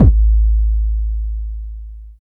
50 KICK 2.wav